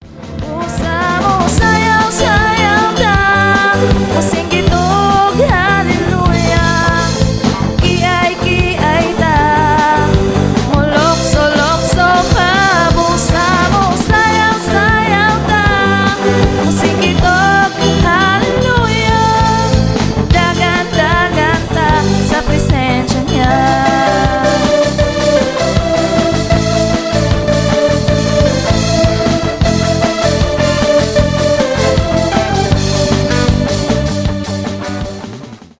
рок
христианские
pop rock